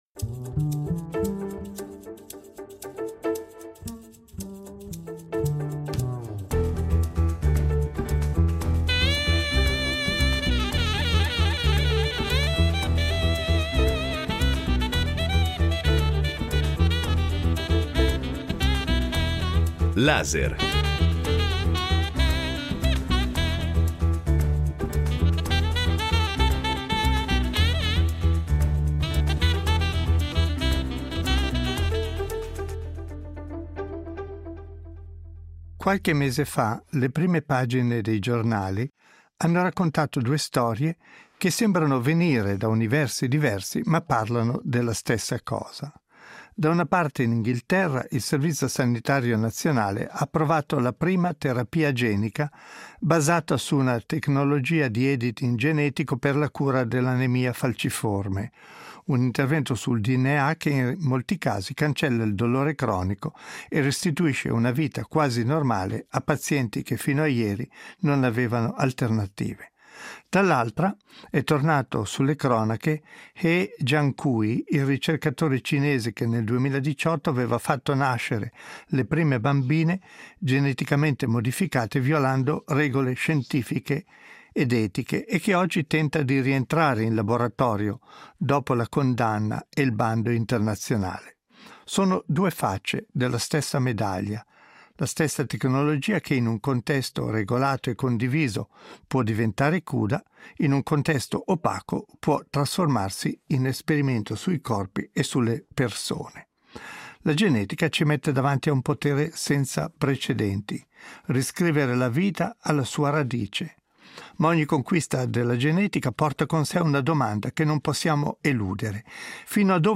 Ne discutono un genetista, una bioeticista, un filosofo della scienza.